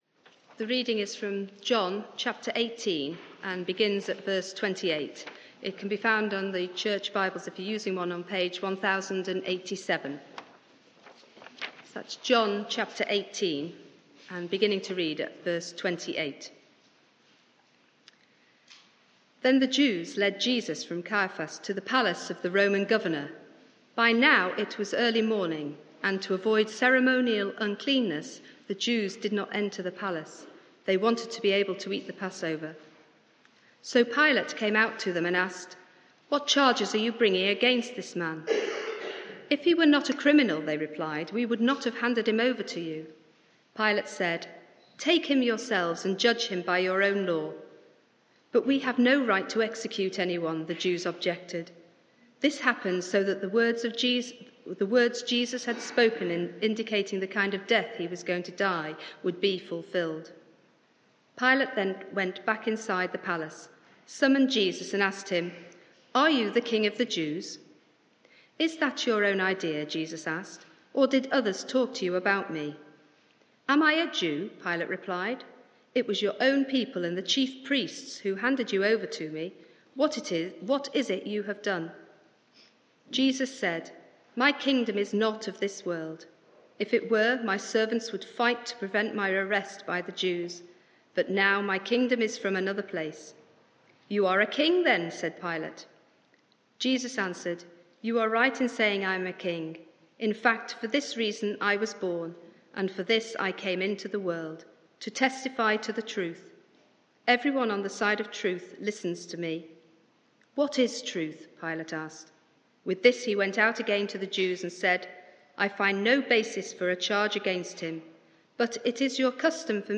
Media for 6:30pm Service on Sun 03rd Apr 2022 18:30 Speaker
Theme: Behold your King Sermon (audio)